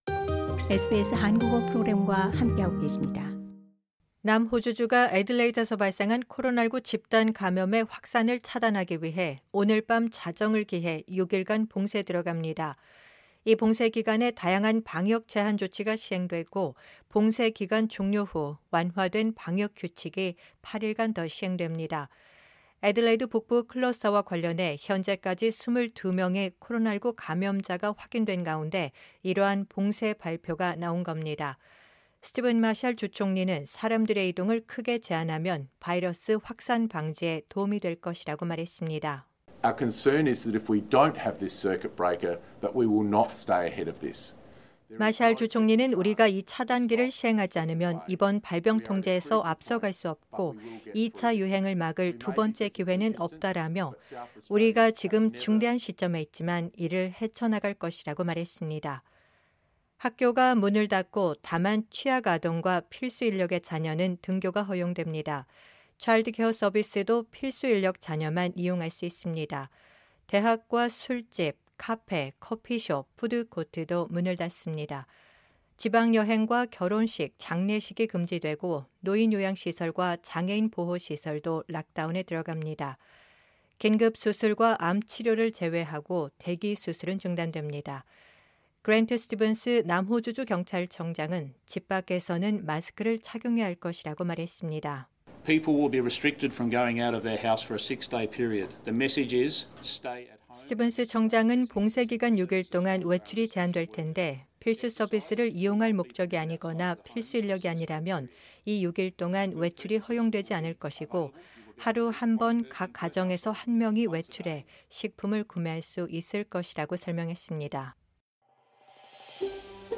korean_1811_audionewssalockdown.mp3